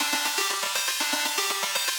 SaS_Arp04_120-A.wav